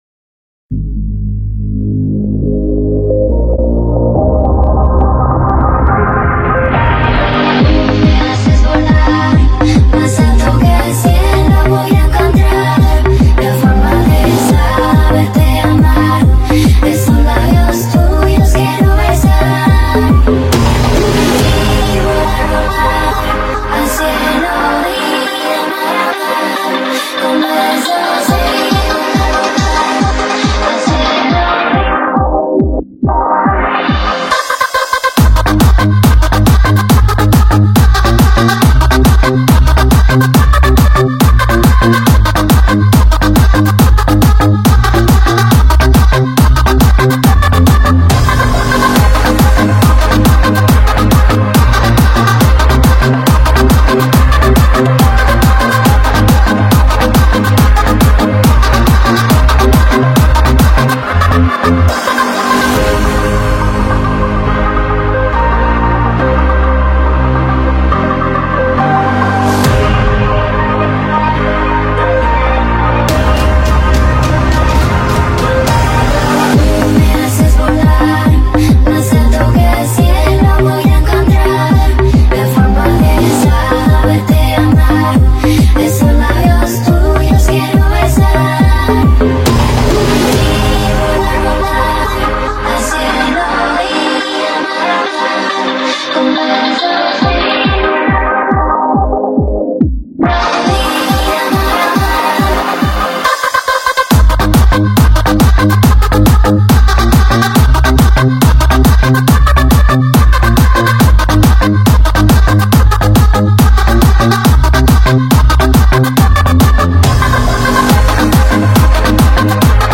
Category : Trending Remix Song